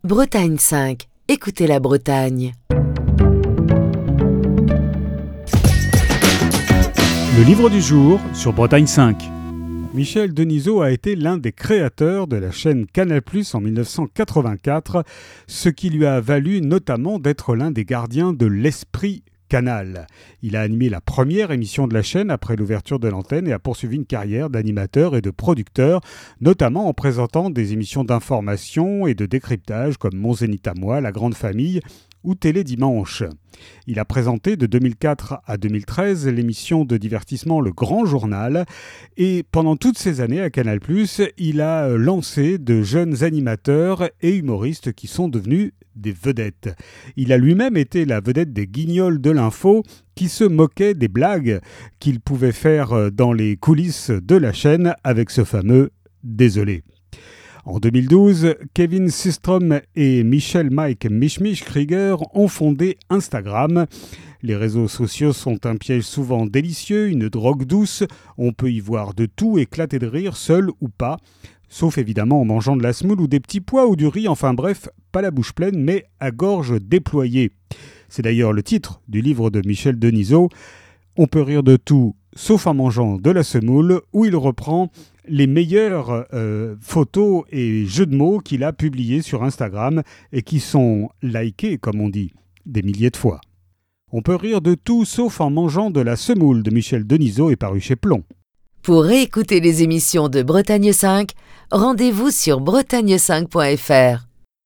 Fil d'Ariane Accueil Les podcasts On peut rire de tout, sauf en mangeant de la semoule - Michel Denisot On peut rire de tout, sauf en mangeant de la semoule - Michel Denisot Chronique du 12 avril 2023.